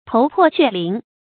头破血淋 tóu pò xuè lín 成语解释 见“头破血流”。